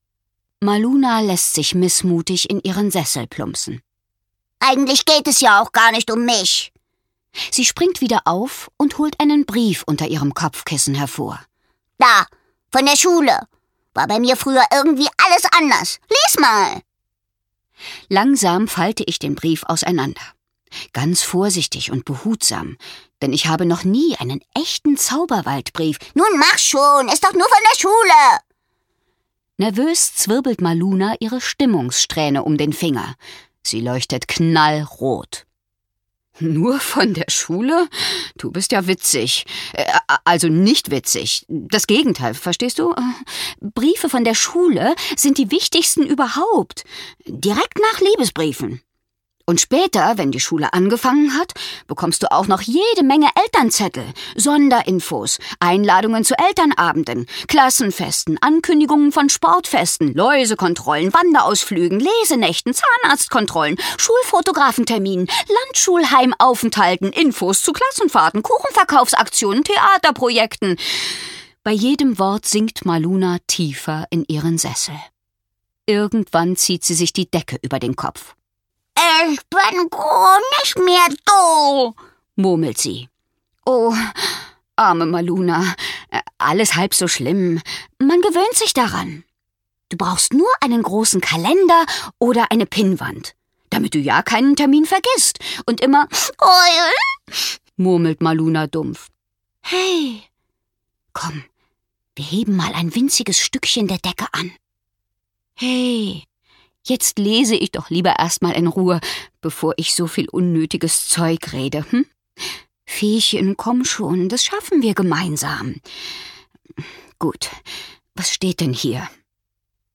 Hörbuch: Maluna Mondschein.